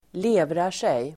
Ladda ner uttalet
levra sig verb, clot , coagulate Grammatikkommentar: x & Uttal: [²l'e:vrar_sej] Böjningar: levrade sig, levrat sig, levra sig, levrar sig Definition: stelna (om blod) Exempel: levrat blod (coagulated blood)